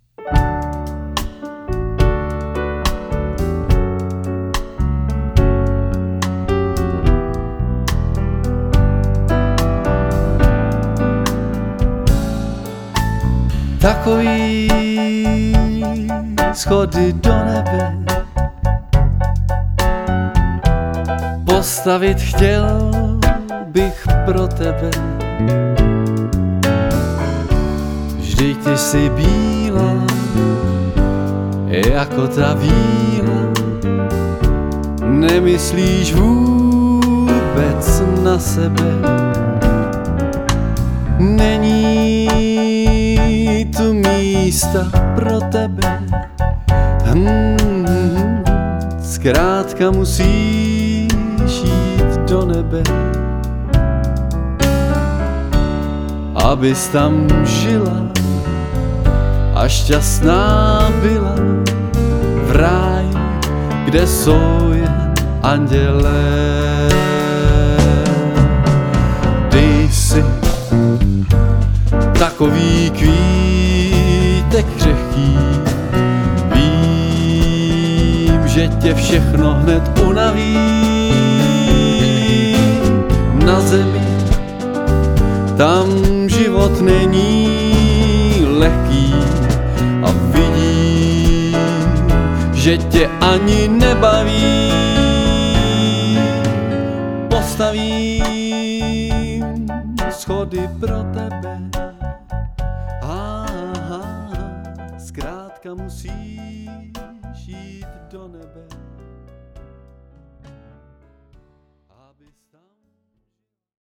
světoznámá dueta, swing, evergreeny, oldies